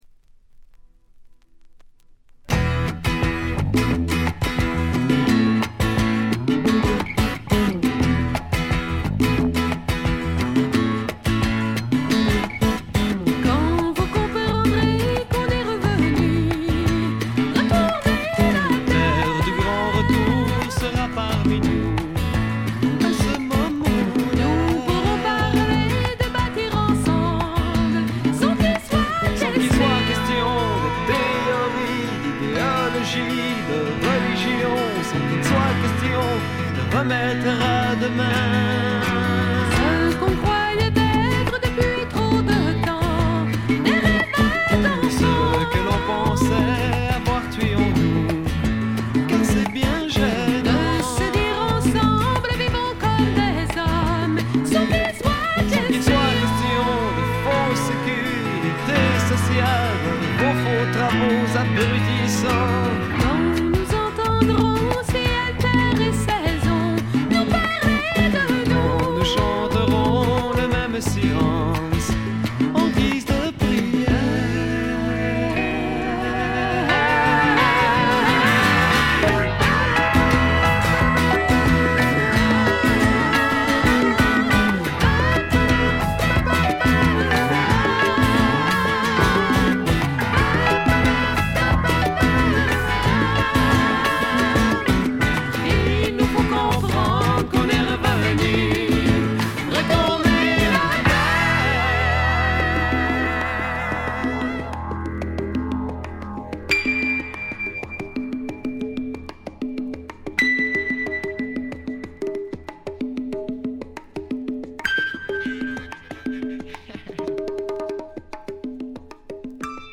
カナダ・ケベックを代表する兄妹フレンチ・ヒッピー・フォーク・デュオによる名盤です。
本作は特に幻想的な表現に磨きがかかっており、浮遊感漂う夢見心地な感覚は絶品ですね。
試聴曲は現品からの取り込み音源です。
Guitar, Vocals